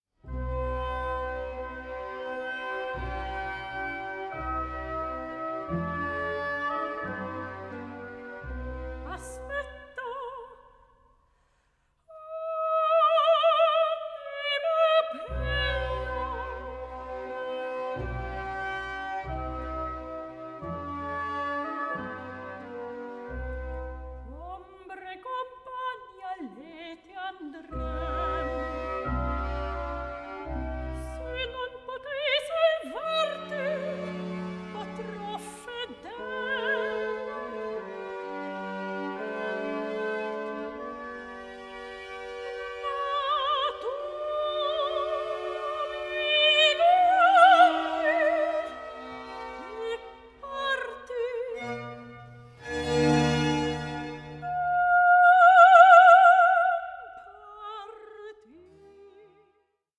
PASSIONATE VOCAL AND SYMPHONIC MUSIC FROM THE CLASSICAL ERA
period-instruments ensembles